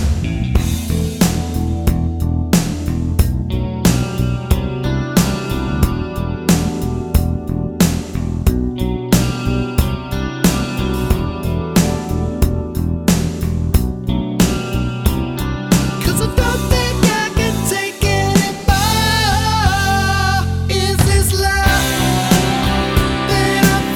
Minus Lead Guitar Solo Rock 4:16 Buy £1.50